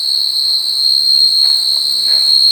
Hier sind sowieso schon jede Menge nervige Geräusche.
• Das Grillenkonzert abends ist wirklich so krass, das tut schon weh in den Ohren. Man denkt, es quietscht irgend ein Getriebe.
00304_pandanlaut_grillenlop.mp3